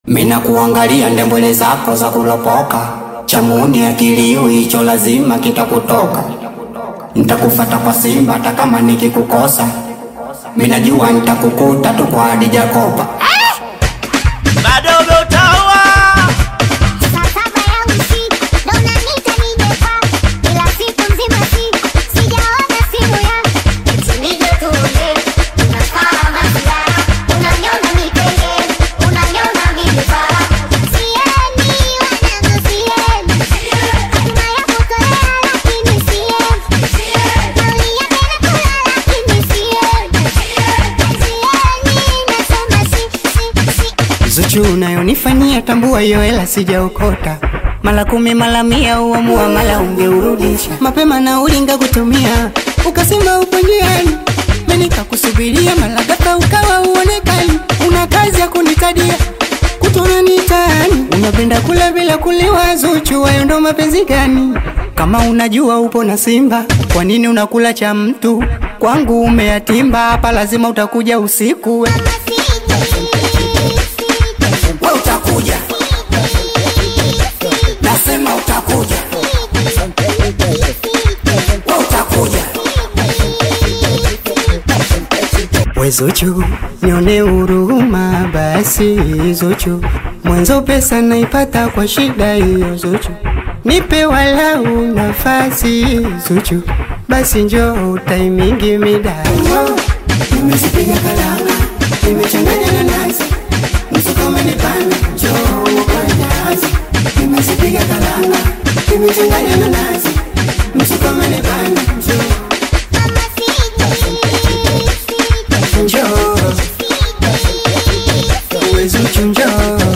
Singeli music track
Bongo Flava